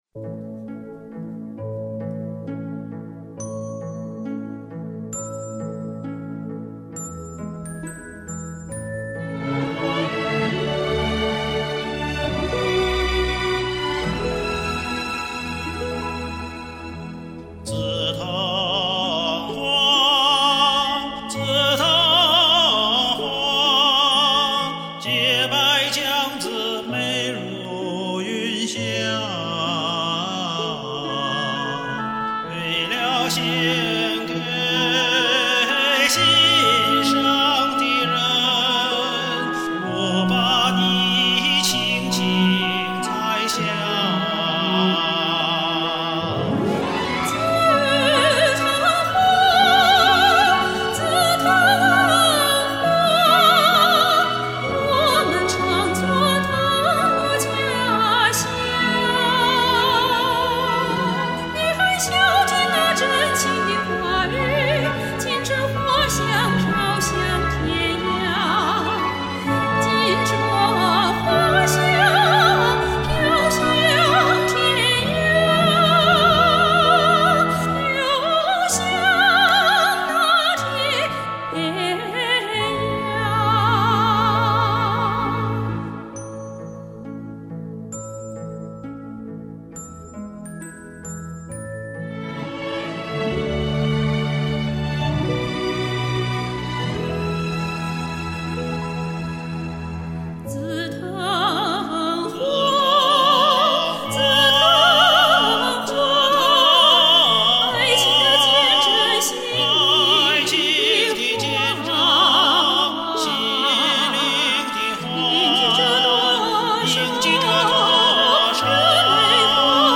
好一对高音金嗓子组合。
也WOW一声，精致优美的合唱！
饱满明亮，缠绵不断！